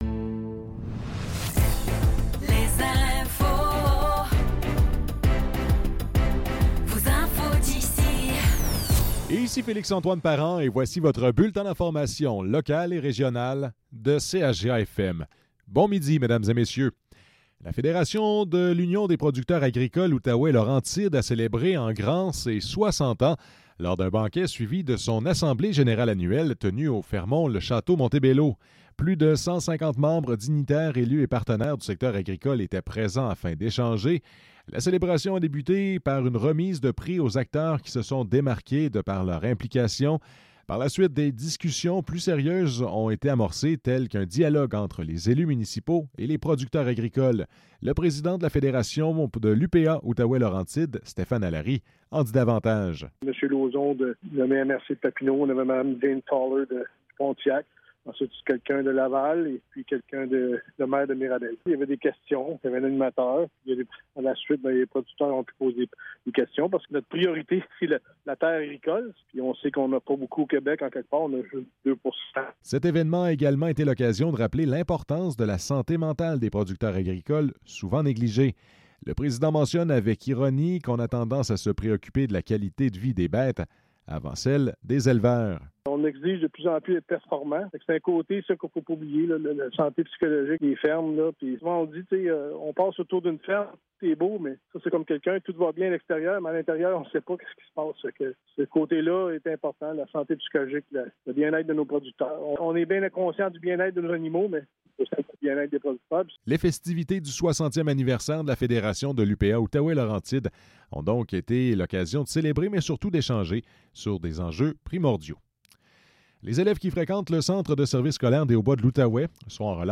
Nouvelles locales - 7 novembre 2024 - 12 h